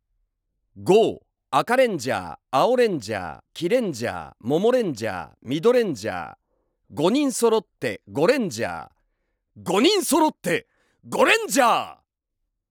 さらに人気声優の関智一氏朗詠による読み上げCDが付属！